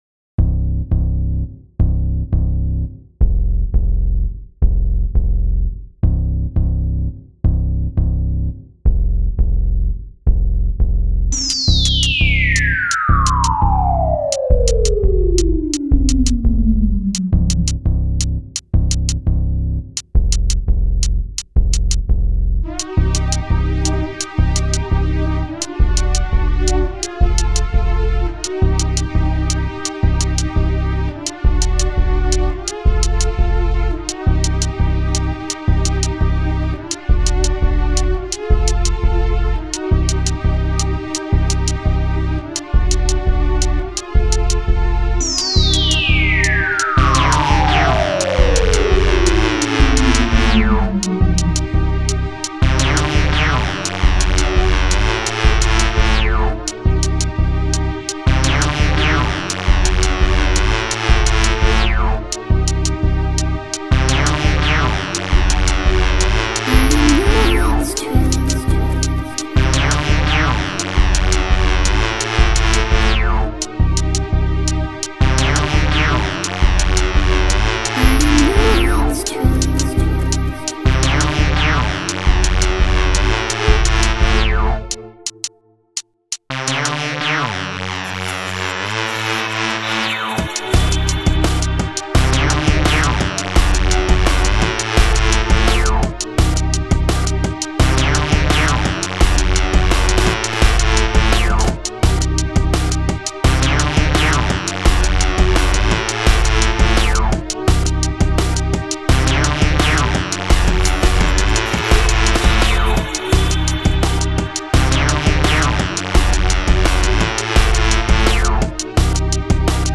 dance/electronic
Drum & bass
Breaks & beats